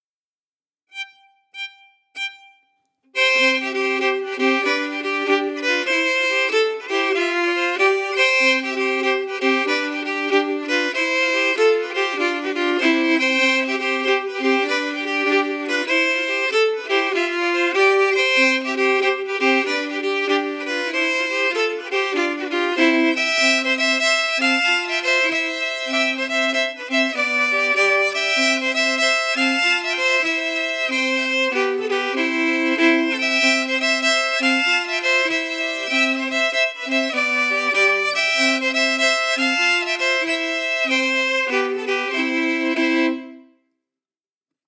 Key: C
Form: Québecois six-huit (Jig)
Genre/Style: Québecois six-huit
Quadrille-Beauharnois-harmonies.mp3